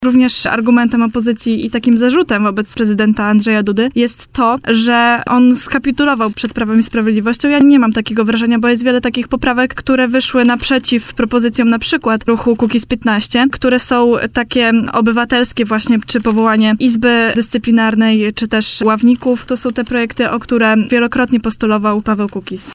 w poranku „Siódma9” na antenie Radia Warszawa